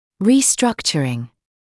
[ˌriː’strʌkʧərɪŋ][ˌриː’стракчэрин]реструктурирование; инговая форма от to restructure